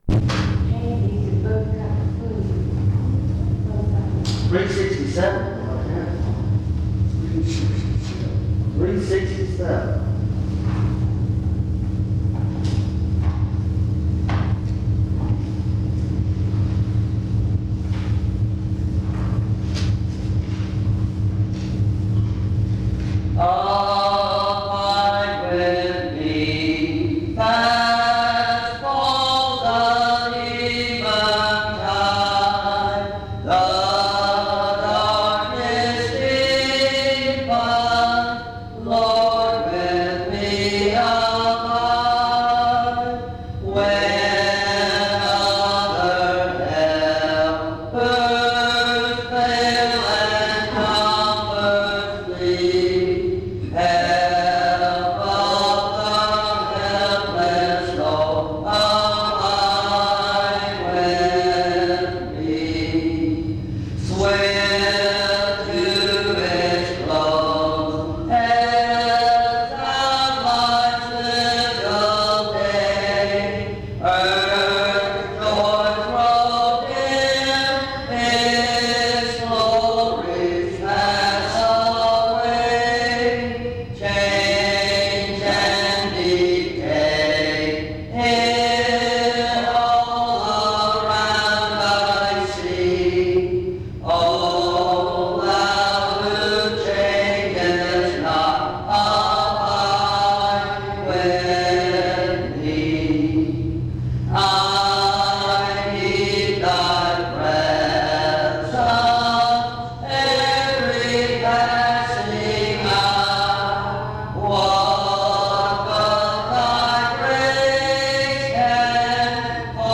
Hebrews 1:1-3; A small portion of this recording seems to be missing.
In Collection: Reidsville/Lindsey Street Primitive Baptist Church audio recordings Miniaturansicht Titel Hochladedatum Sichtbarkeit Aktionen PBHLA-ACC.001_066-B-01.wav 2026-02-12 Herunterladen PBHLA-ACC.001_066-A-01.wav 2026-02-12 Herunterladen